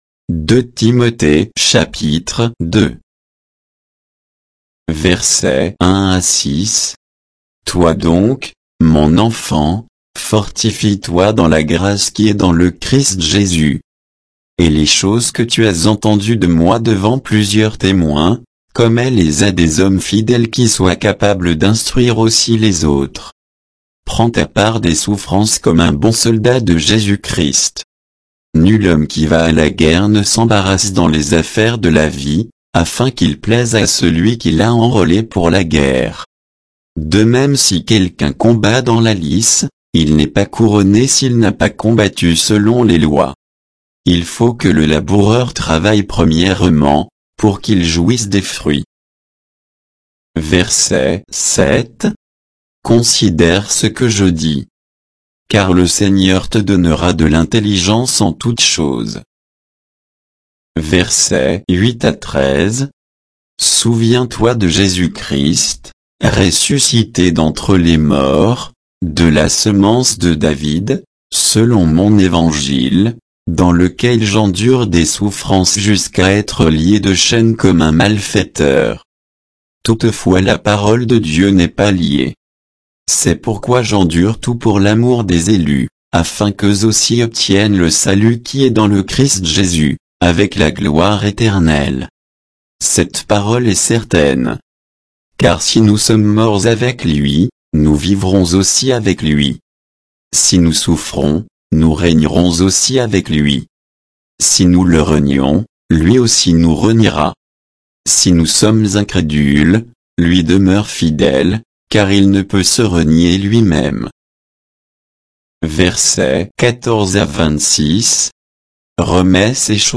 Bible_2_Timothée_2_(sans_notes,_avec_indications_de_versets).mp3